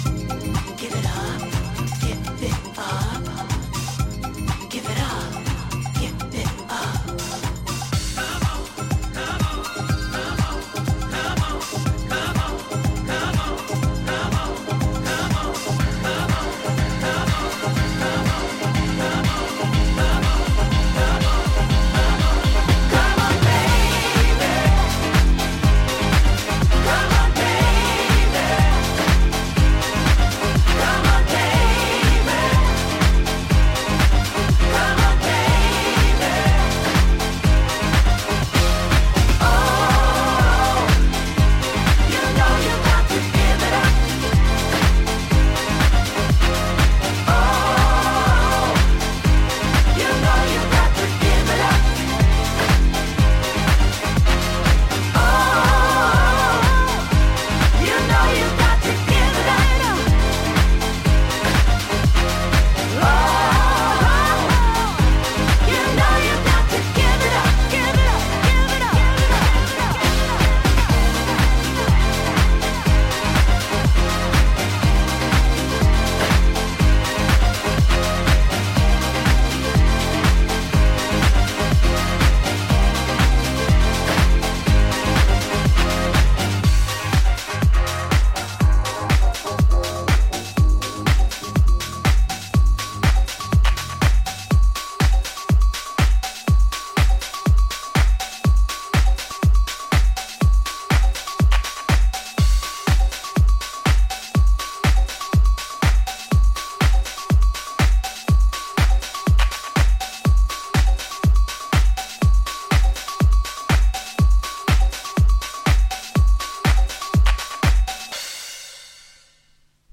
Up next the floor filling, gospel tinged "